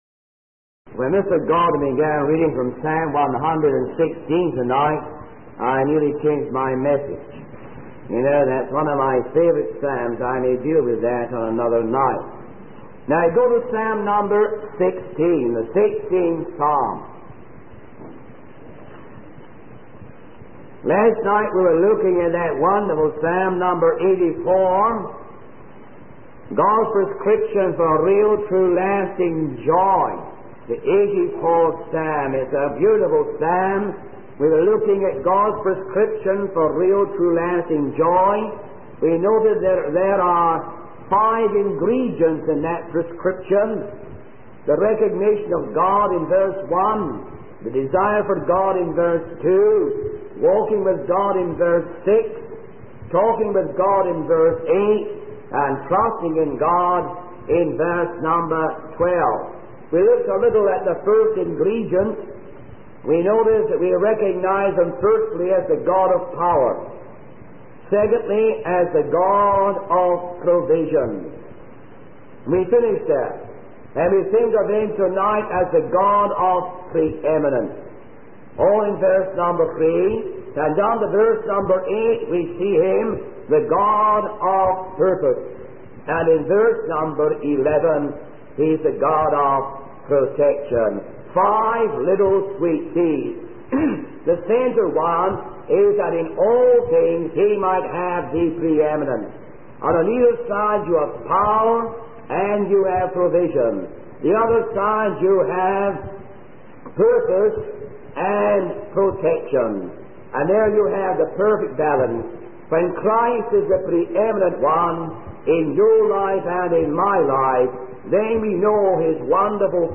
In this sermon, the preacher discusses the concept of being changed in a moment, specifically referring to the biblical passage that mentions being changed in the twinkling of an eye.